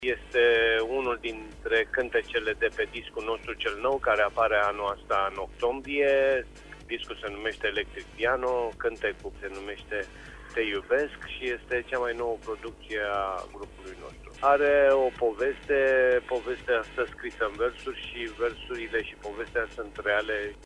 Liderul trupei Marian Ionescu a afirmat în exclusivitate pentru Radio România Reșița: